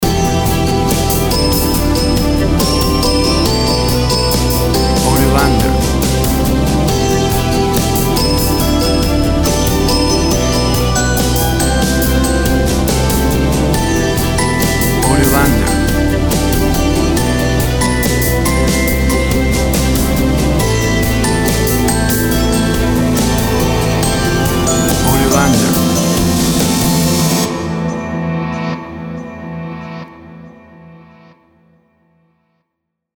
Drama and nostalgic ambient rock sounds.
Tempo (BPM) 70